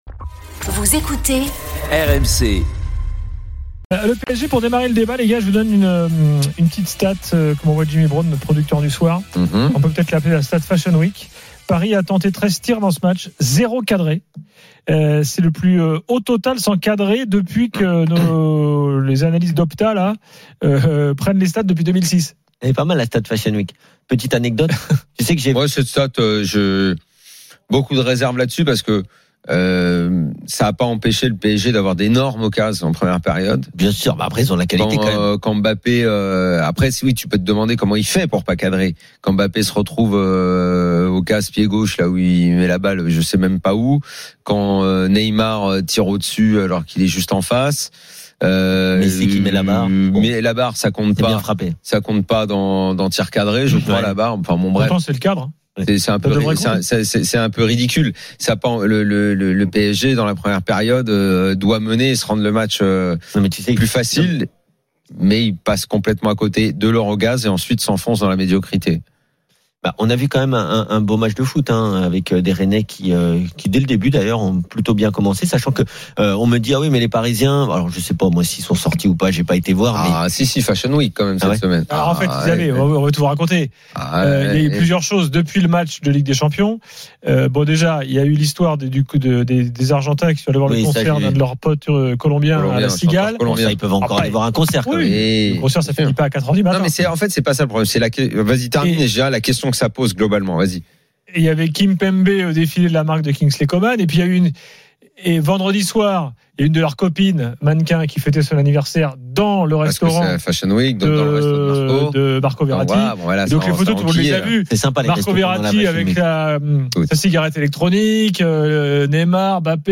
Chaque jour, écoutez le Best-of de l'Afterfoot, sur RMC la radio du Sport !
L'After foot, c'est LE show d'après-match et surtout la référence des fans de football depuis 15 ans !